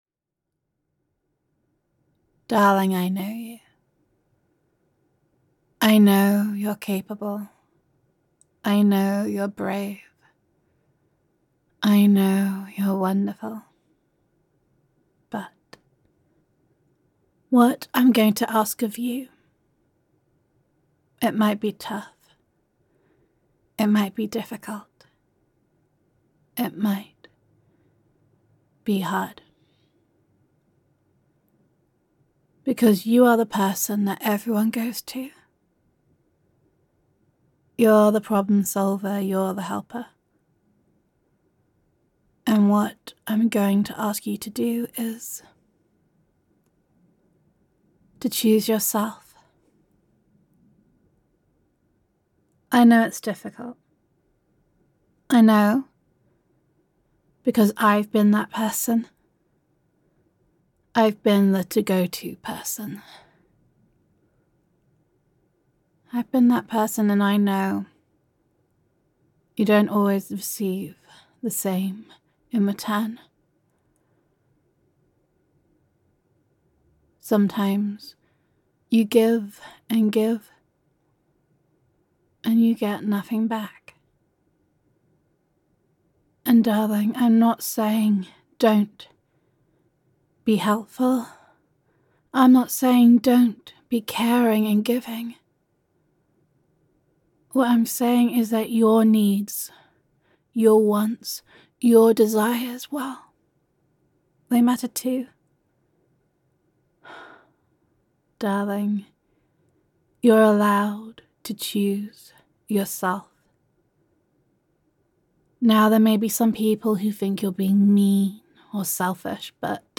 NEW CARING GIRLFRIEND AUDIO
[F4A] Choose Yourself [Give and Take][Make Yourself a Priority][Take Care of Yourself][Gender Neutral][Caring Girlfriend Roleplay]